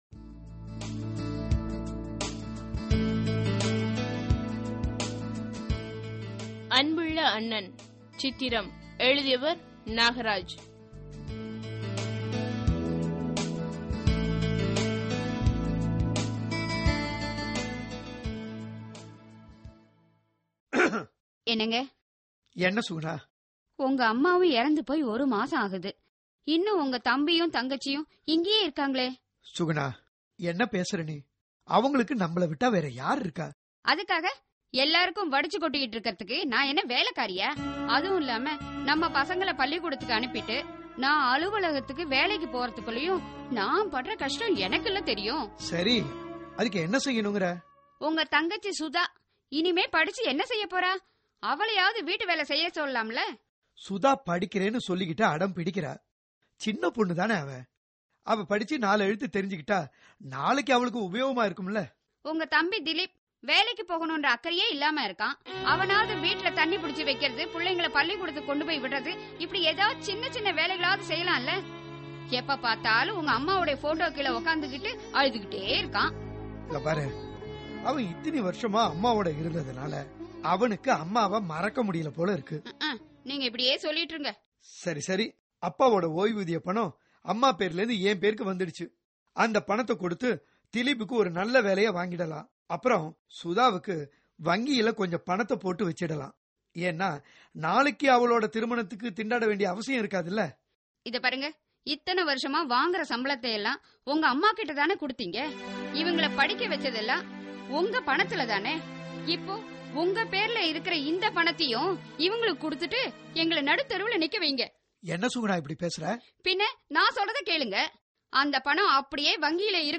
Directory Listing of mp3files/Tamil/Dramas/Social Drama/ (Tamil Archive)